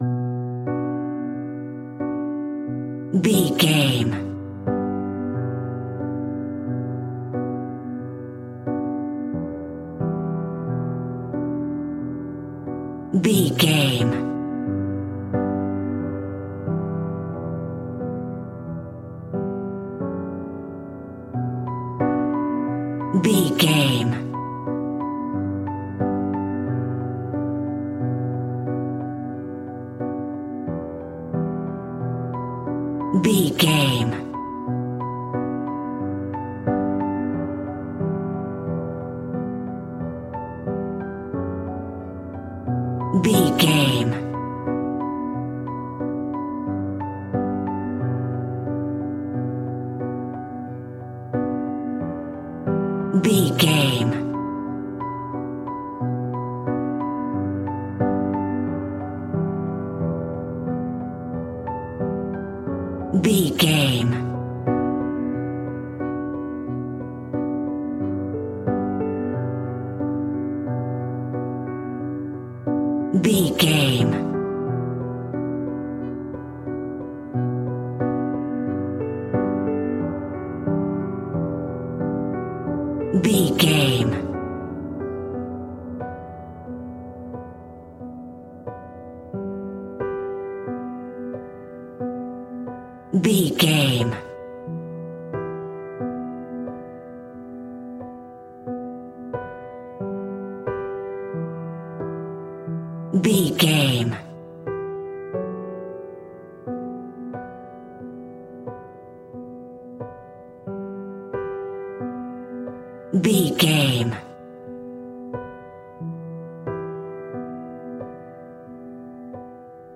A bouncy and fun classical piano melody.
Regal and romantic, a classy piece of classical music.
Aeolian/Minor
regal
romantic